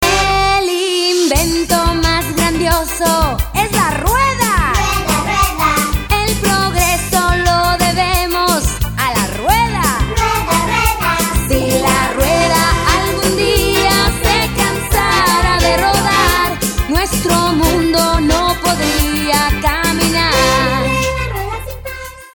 children's music